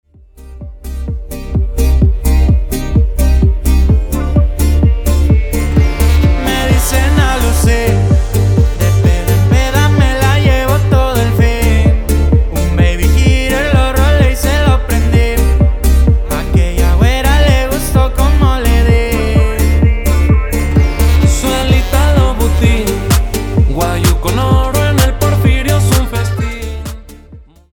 Extended Dirty Intro
Electronica